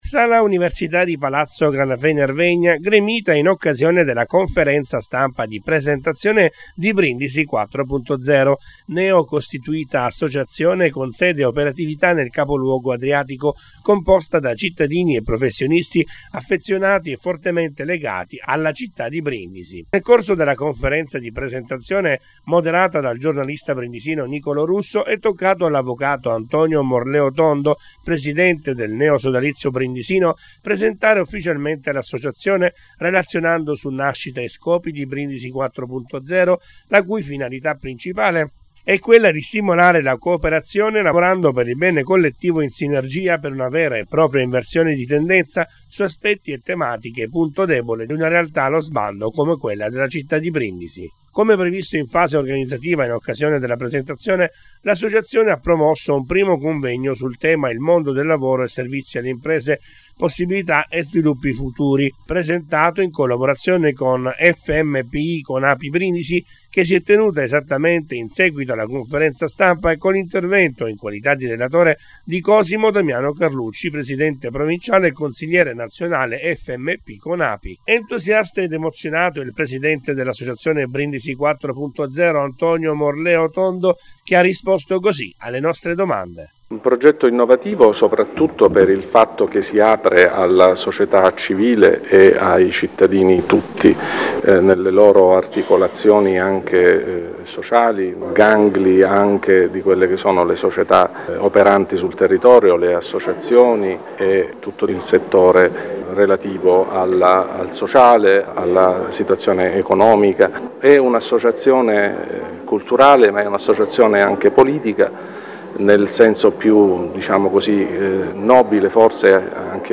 Sala Università di Palazzo Granafei-Nervegna gremita in occasione della conferenza stampa di presentazione di Brindisi 4.0, neo costituita associazione con sede e operatività nel Capoluogo Adriatico composta da cittadini e professionisti affezionati e fortemente legati alla città di Brindisi.
Presentata-nel-capoluogo-lassociazione-Brindisi-4.0-Servizo-Idea-Radio.mp3